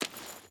Footsteps / Water
Water Chain Run 5.ogg